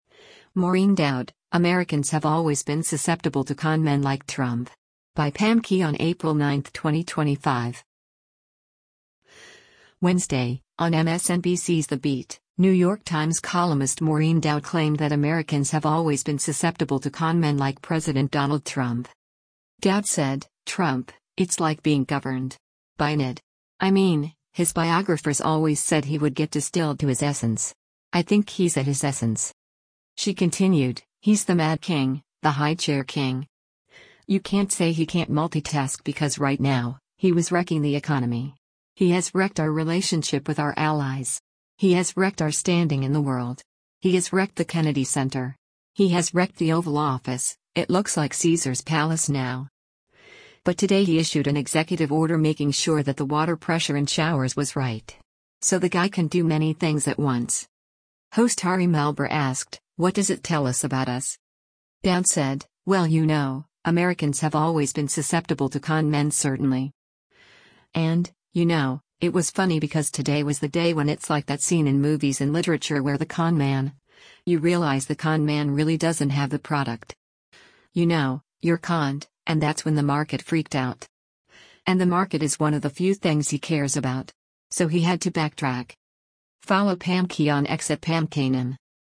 Wednesday, on MSNBC’s “The Beat,” New York Times columnist Maureen Dowd claimed that Americans “have always been susceptible to con men” like President Donald Trump.
Host Ari Melber asked, “What does it tell us about us?”